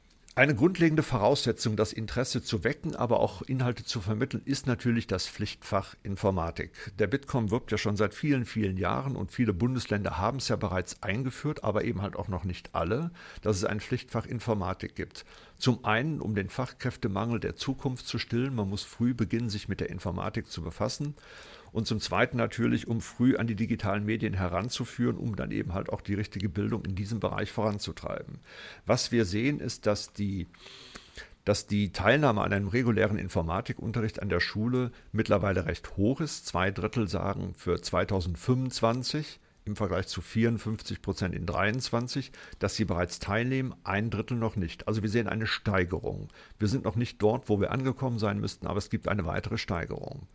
Mitschnitte der Pressekonferenz
bitkom-pressekonferenz-digitale-schule-2025-informatikunterricht.mp3